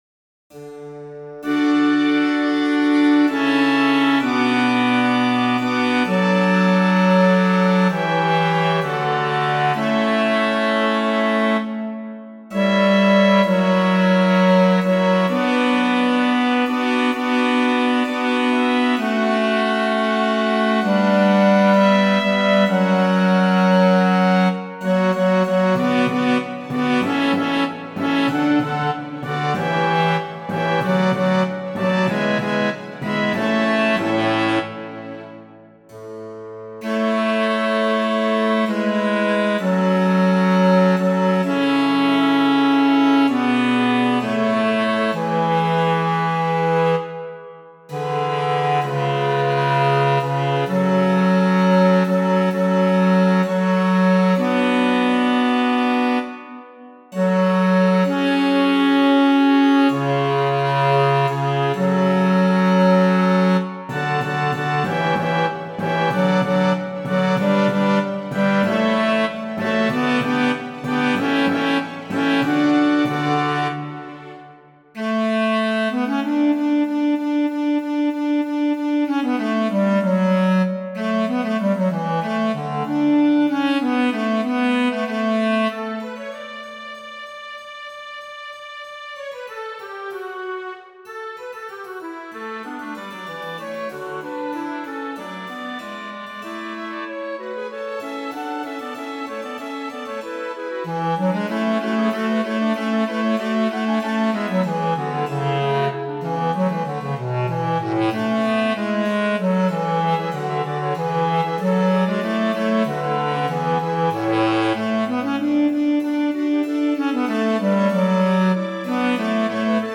Chorus 53_Worthy-Is-The-Lamb__AllEqual.mp3
53_Worthy-Is-The-Lamb__Bass.mp3